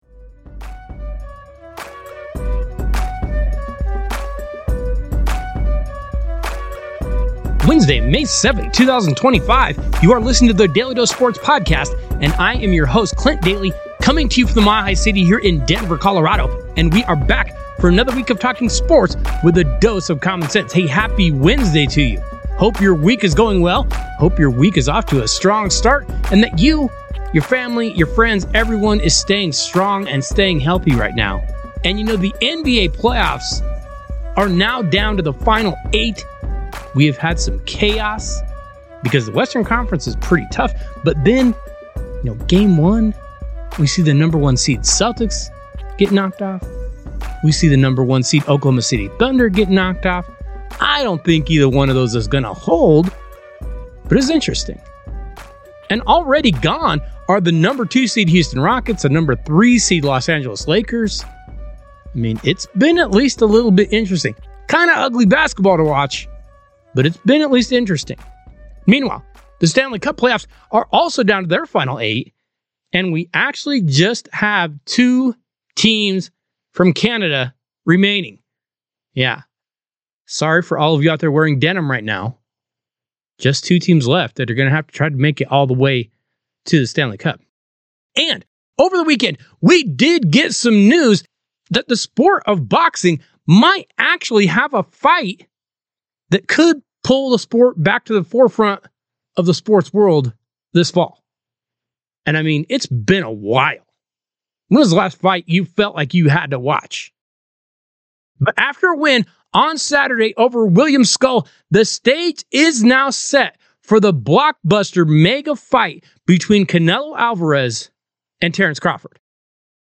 You won't want to miss this powerful and insightful conversation!